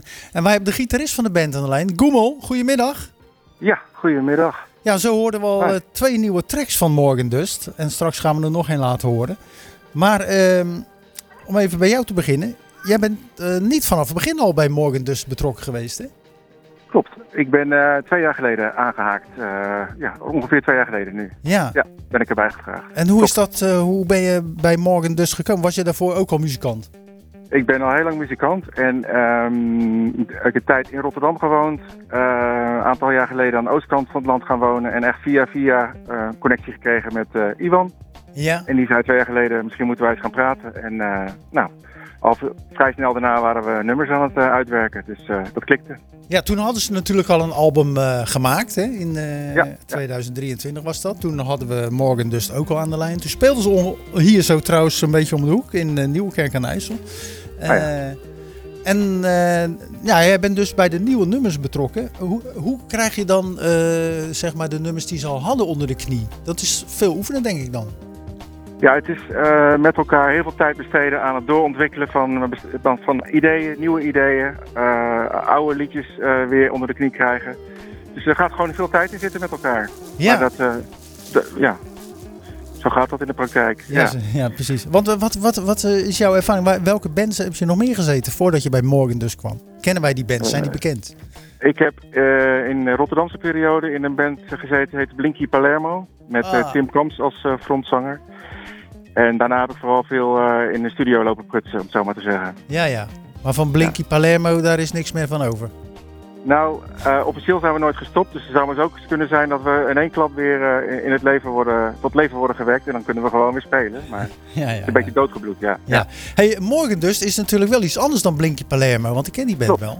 Tijdens de uitzending van Zwaardvis